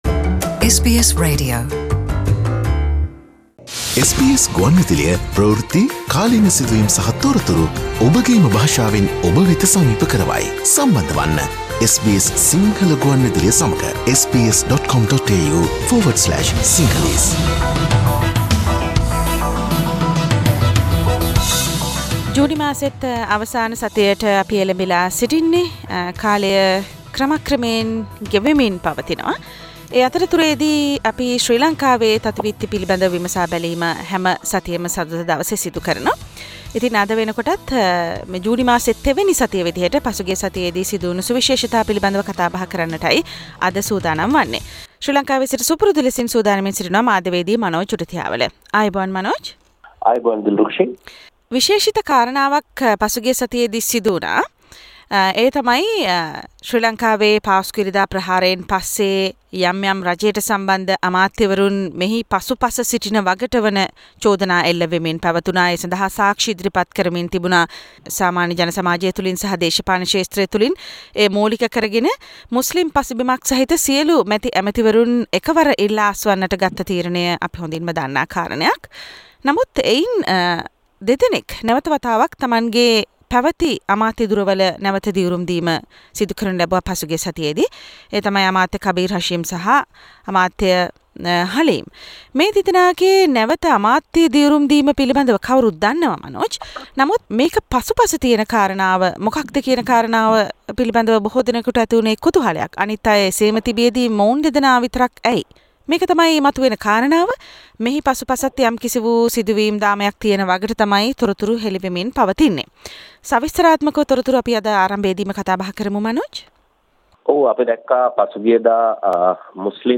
කබීර් සහ හලීම් අමාත්‍යවරුන්ගේ යලි දිවුරුම් දීම සිදුව ඇත්තේ රනිල් ගේ අනුදැනුමකින් තොරව බව හෙළිවෙයි: සතියේ ශ්‍රී ලංකික දේශපාලන පුවත් විග්‍රහය